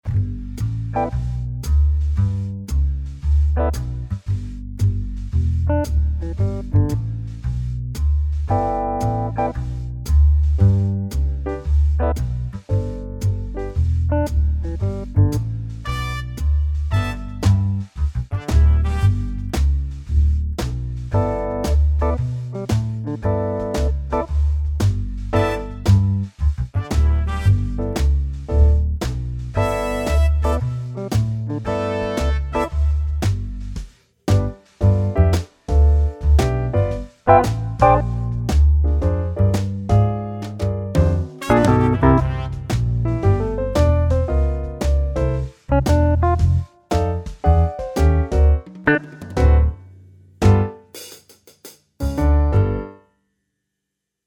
BASS= Trilian VST
DRUMS = Superior Drummer VST
GUITAR= Warm Jazz
PIANO= Pianoteq VST (Grotrian)
BRASS = Session Horns VST / Native Instruments
Er...  :-[ c'est seulement un test d'un seul accord sur les 4 variations et ending d'un rhitme Fox, sans melodie main droite, même sans reglage des effets